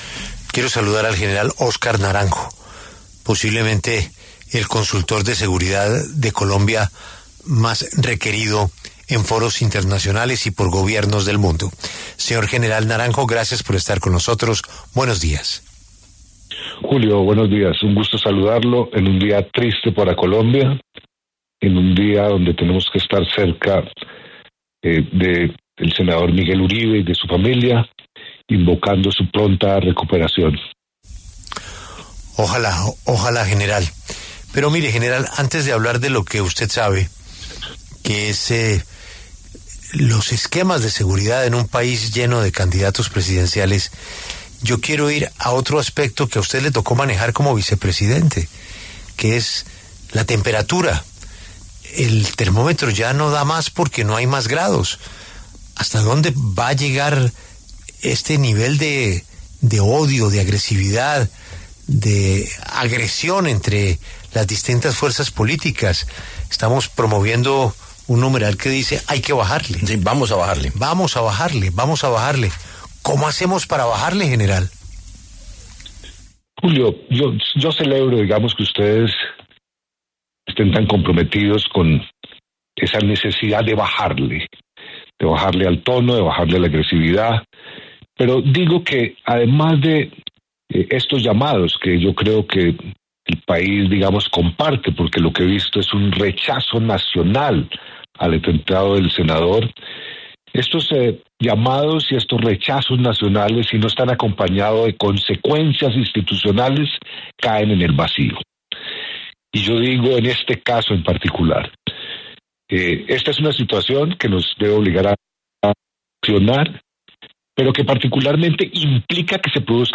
El general Óscar Naranjo habló en La W sobre el atentado del que fue víctima el senador Miguel Uribe el pasado 7 de junio.